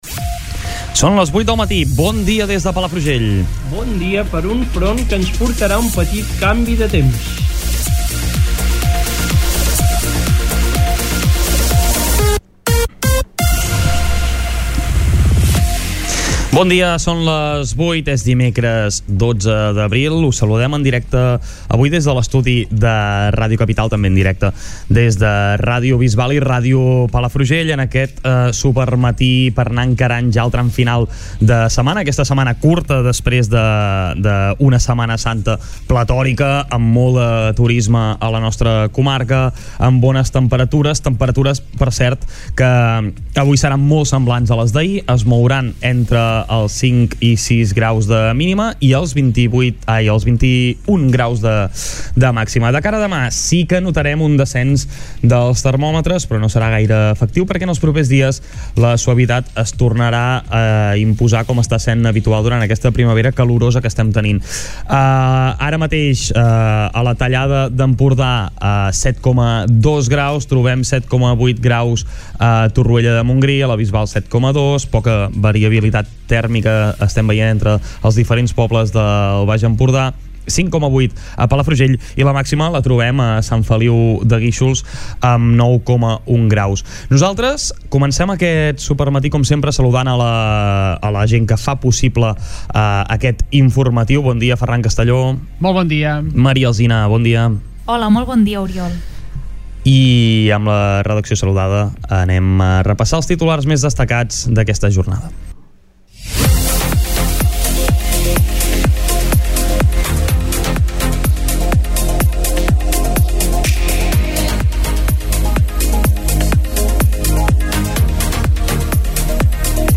Escolta l'informatiu d'aquest dimecres